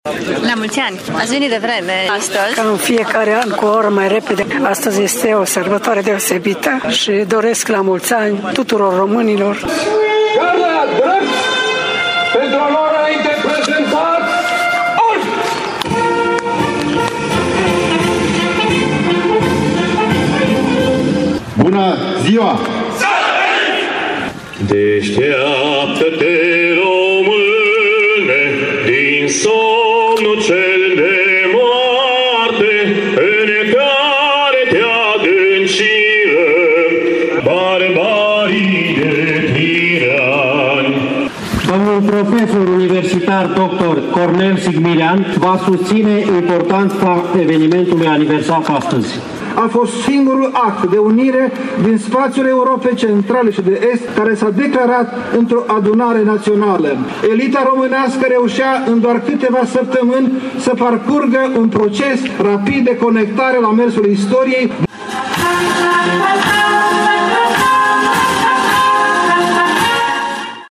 Parada militară de ziua națională a scos din casă mii de târgumureșeni
La final, a defilat garda de onoare și toate efectivele unităților militare de intervenție mureșene: